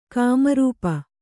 ♪ kāmarūpa